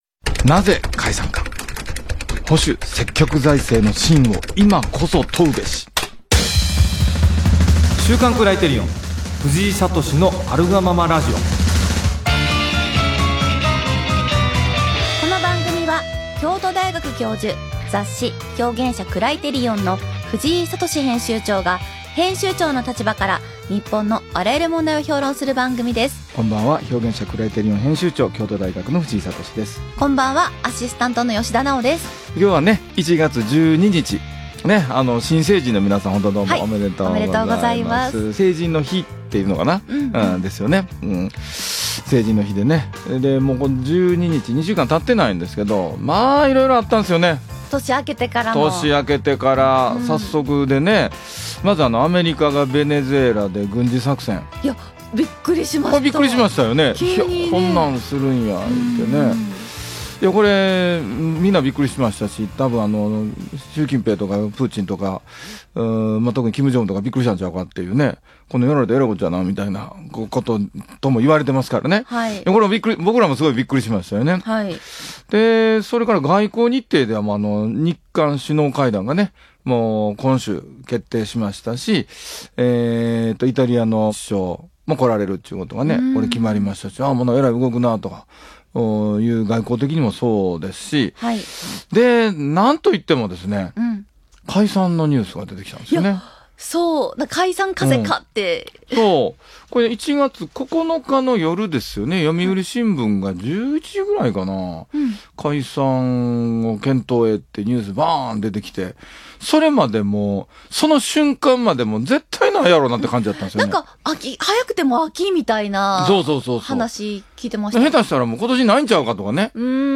【ラジオ】なぜ、解散か？ 「保守・積極財政」の信を、今こそ問うべし